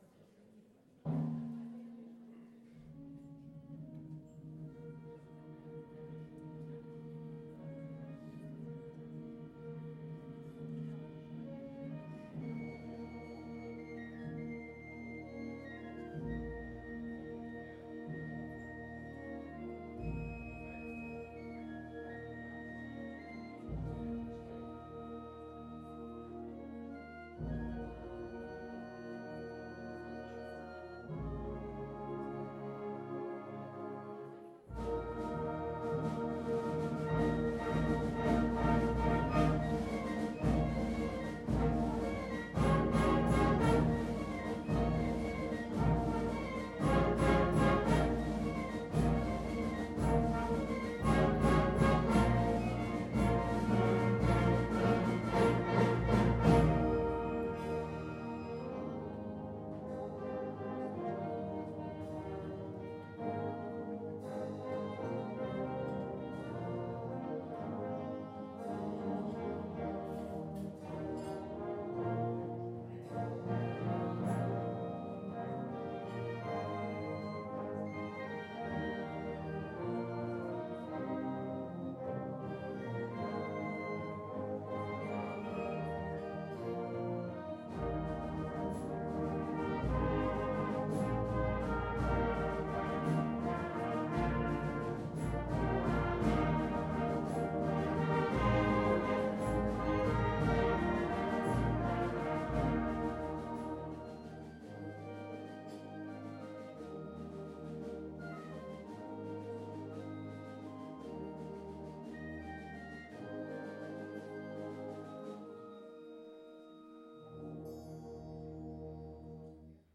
Frühlingskonzert in Remscheid
Einen kleinen Eindruck vom Konzert könnt ihr hier gewinnen:
Brave – Filmmusik zu Merida – Legende der Highlands